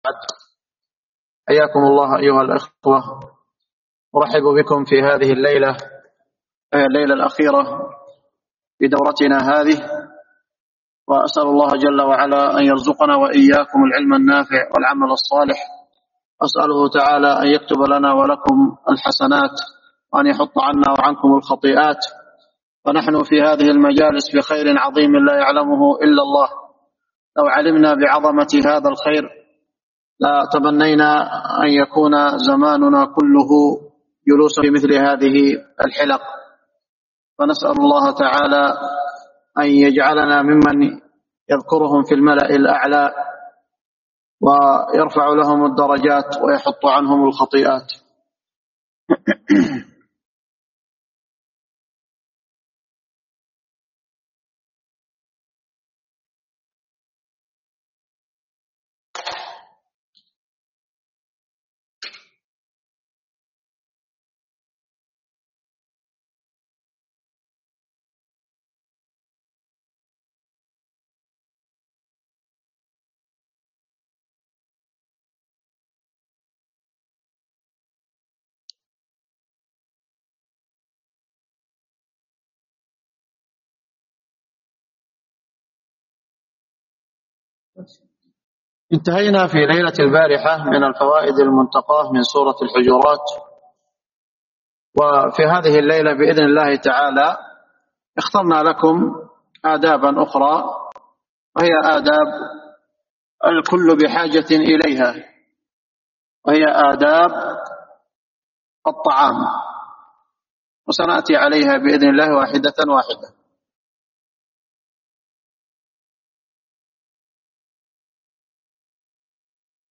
ضمن فعاليات الدورة الصيفية الشرعية للصم وضعاف السمع